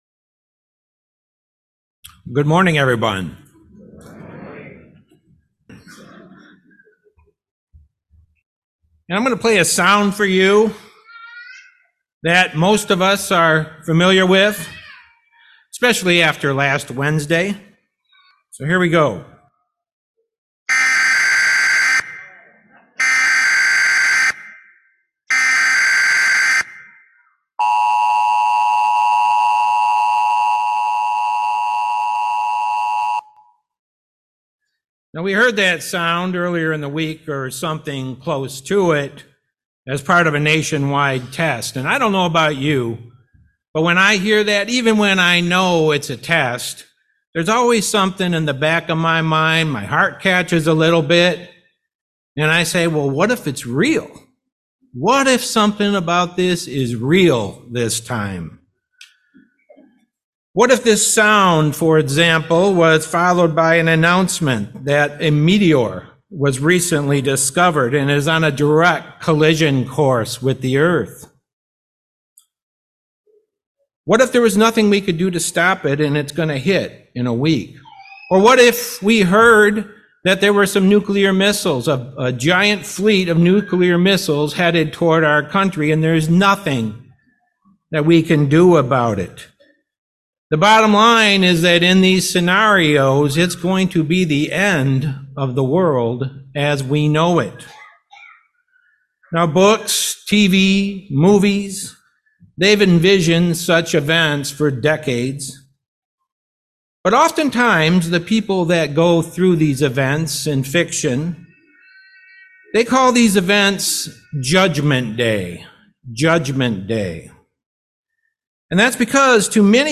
This sermon was given at the Lake Geneva, Wisconsin 2023 Feast site.